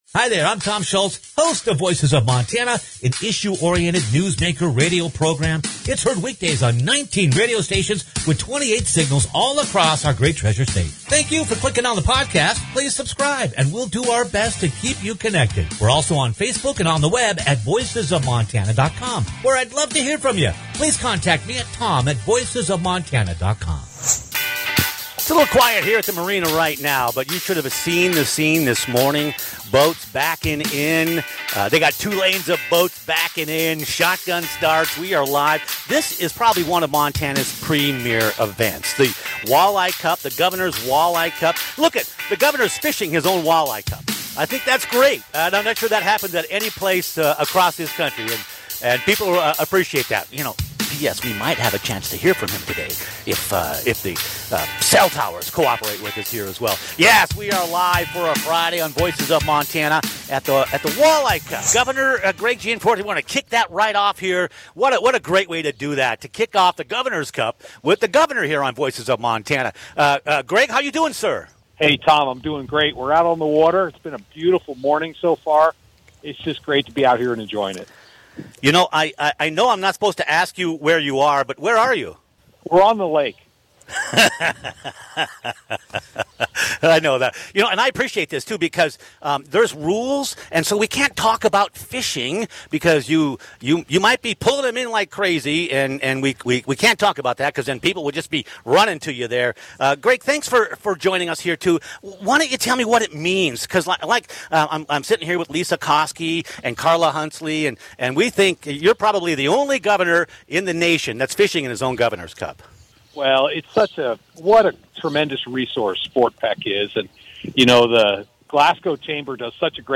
Montana 406’in: Live from the Governor’s Walleye Cup - Voices of Montana
montana-406in-live-from-the-governors-walleye-cup.mp3